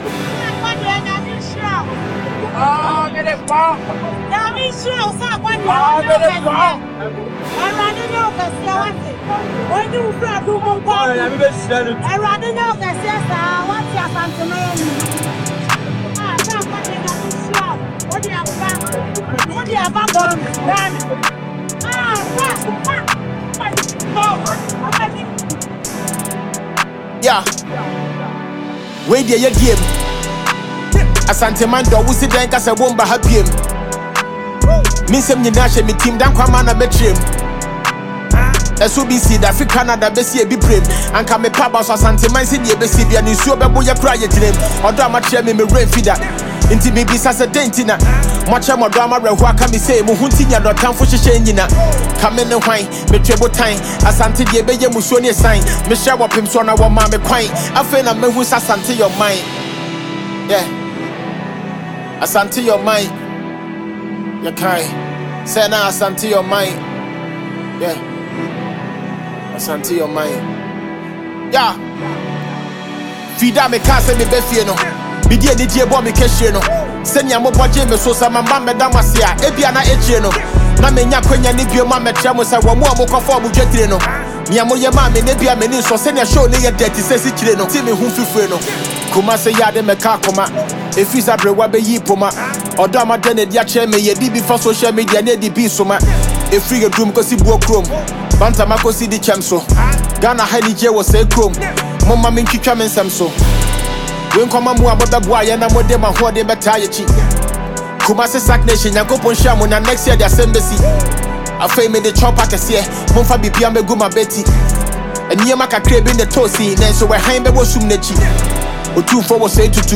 Enjoy this new tune from Ghanaian rapper